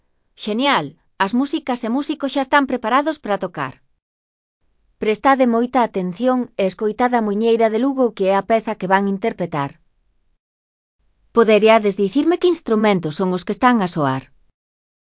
Grupo de pandereteiras
As músicas e músicos xa están preparados para tocar. Prestade moita atención e escoitade a Muiñeira de Lugo que é a peza que van interpretar.
Instrumentos tradicionais galegos